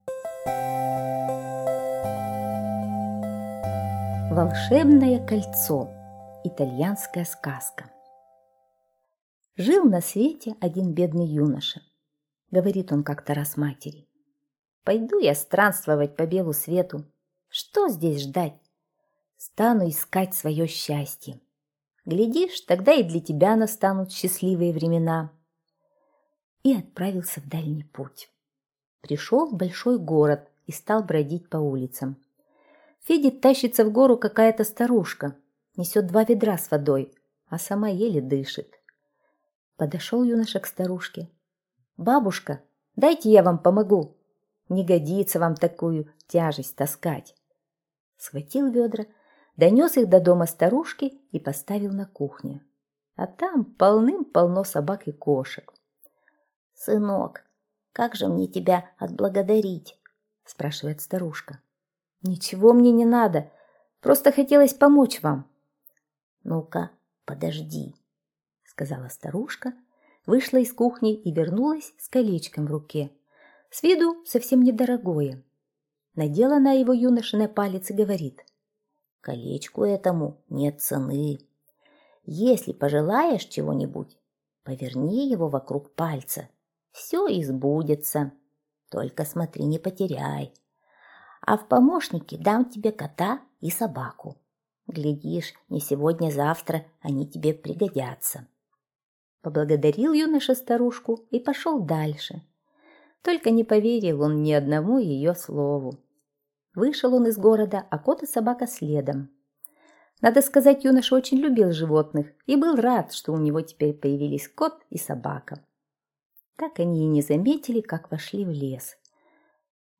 Волшебное кольцо - итальянская аудиосказка - слушать